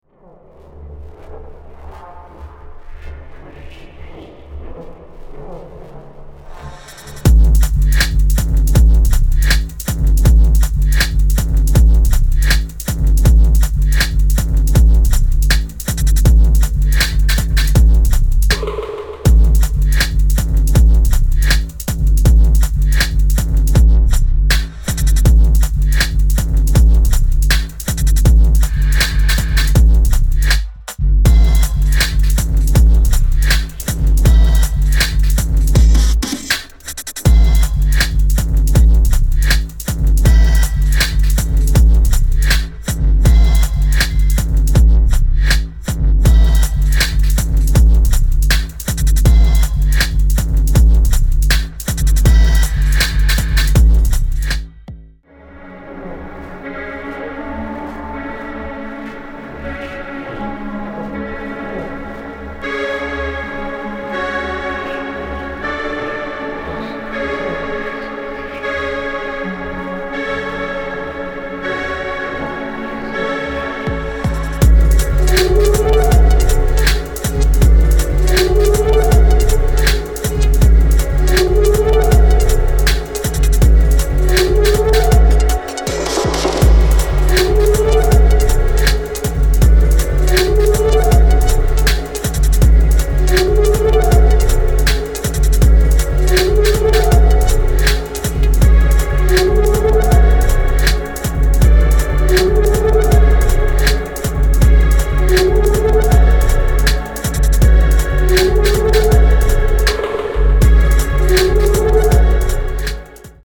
immaculately sculpted techno locked in the studio together.
Electronix Techno